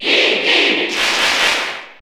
Category: Crowd cheers (SSBU) You cannot overwrite this file.
Iggy_Cheer_Italian_SSB4_SSBU.ogg